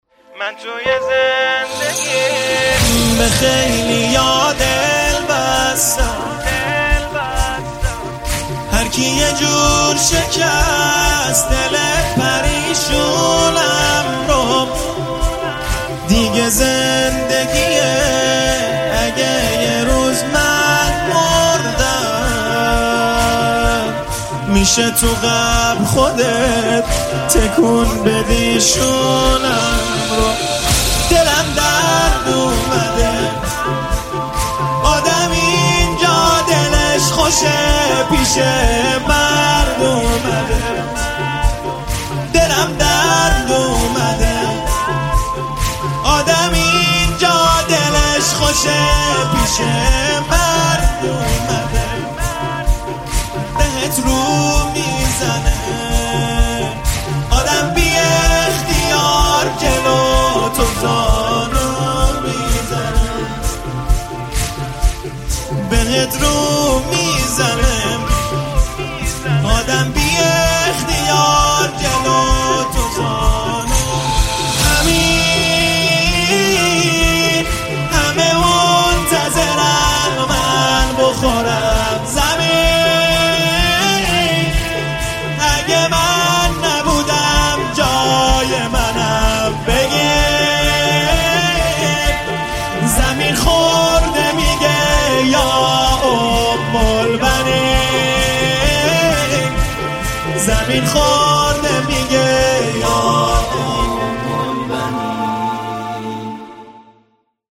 نماهنگ دلنشین